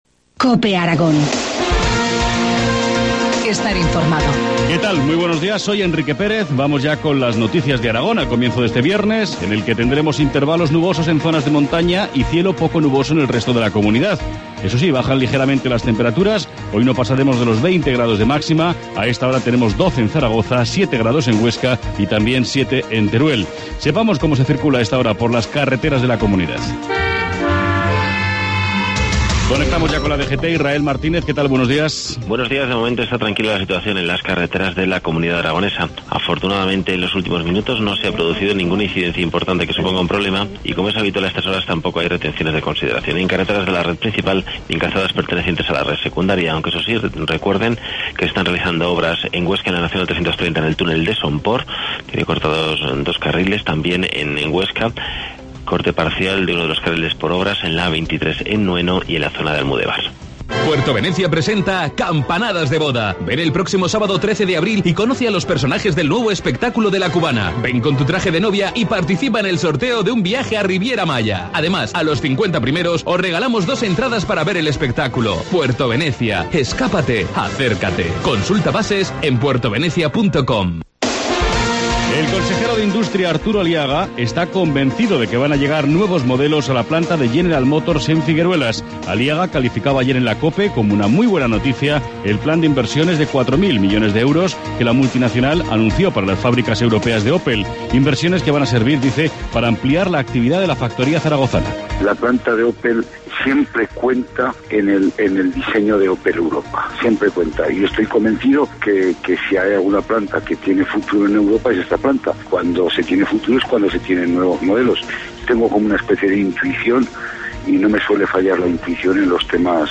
Informativo matinal, viernes 12 de abril, 7.25 horas